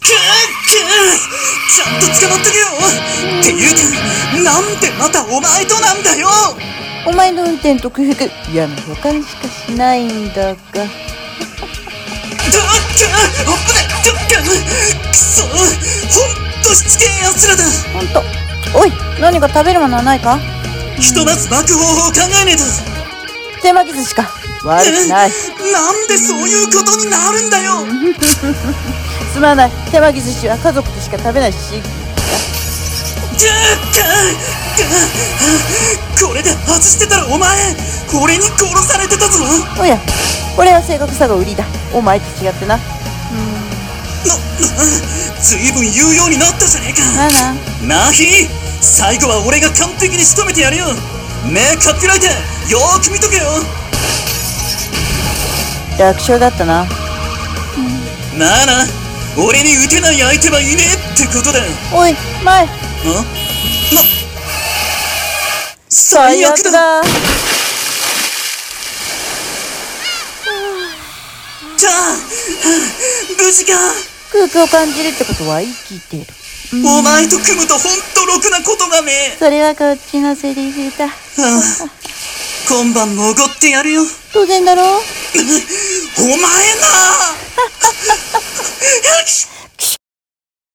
さんの投稿した曲一覧 を表示 【声劇】かりそめBuddy！カーチェイス編 / ▶高木:。